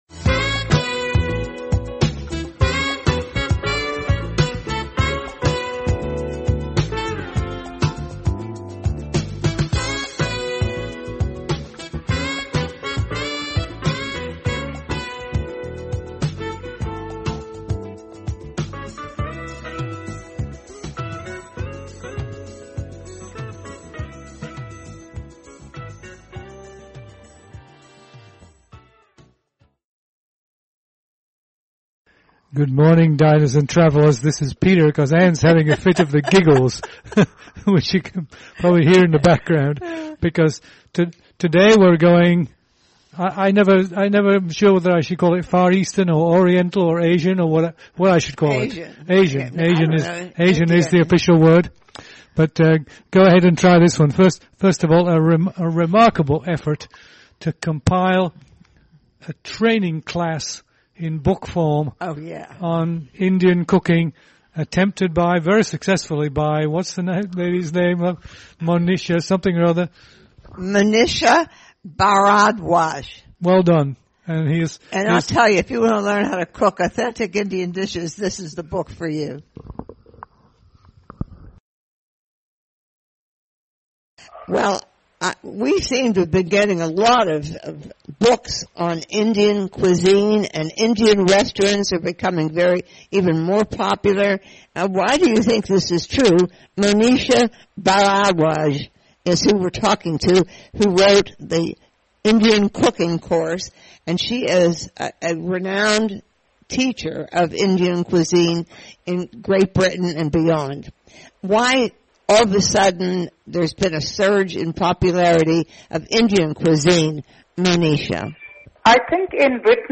Play Rate Listened List Bookmark Get this podcast via API From The Podcast A husband and wife duo, they interview chefs, restaurateurs, hoteliers, authors, winemakers, food producers, cookware and kitchen gadget makers and other culinary luminaries.